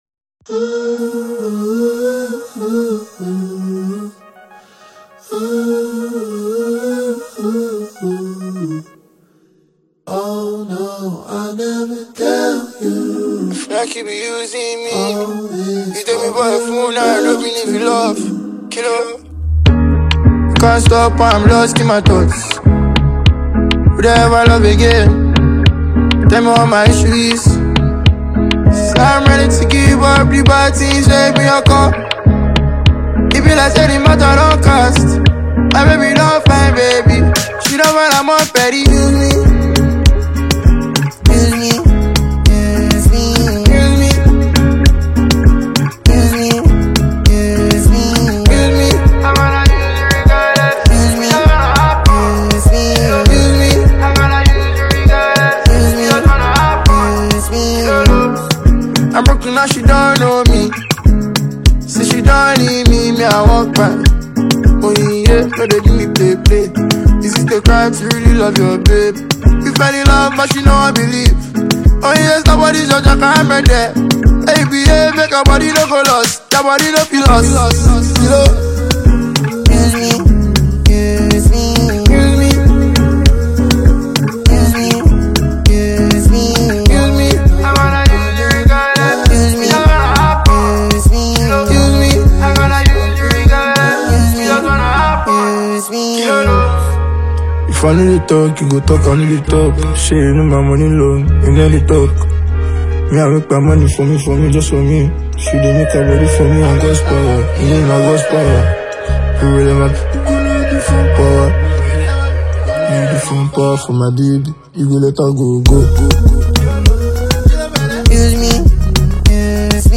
It’s relatable, catchy, and easy to put on repeat.